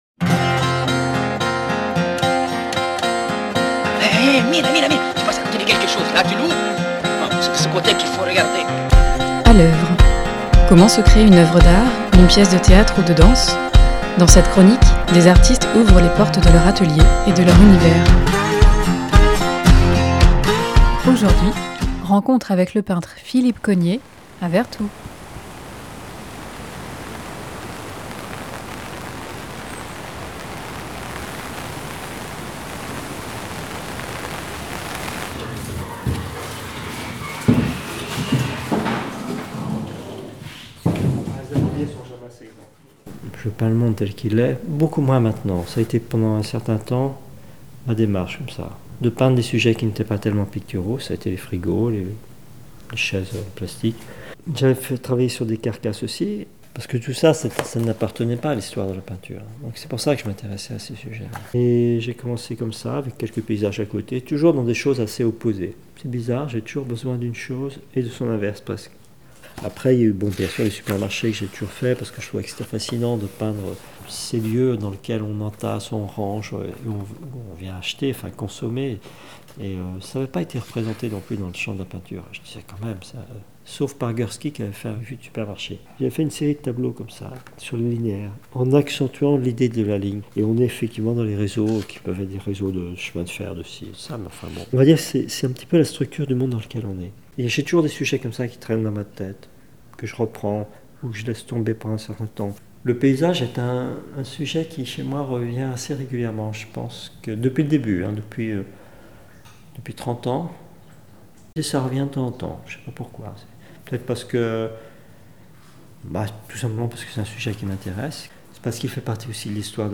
Rencontre avec Philippe Cognée dans son atelier, à Vertou.